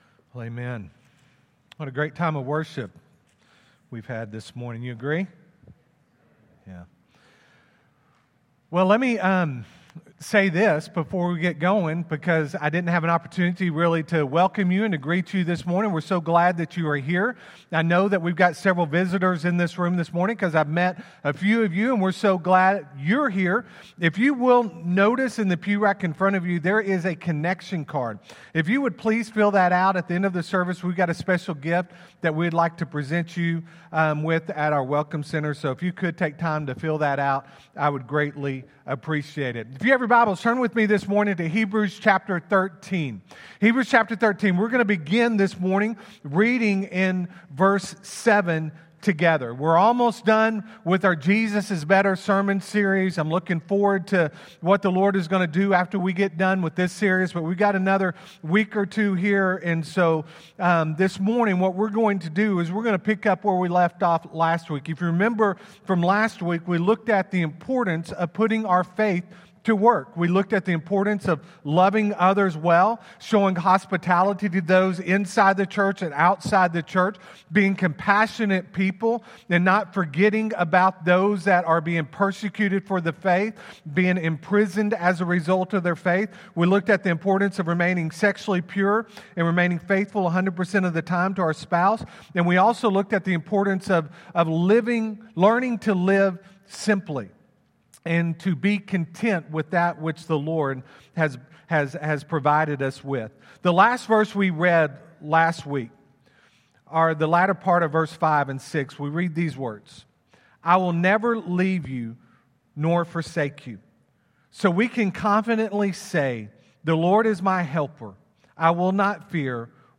Friendship Baptist Church SERMONS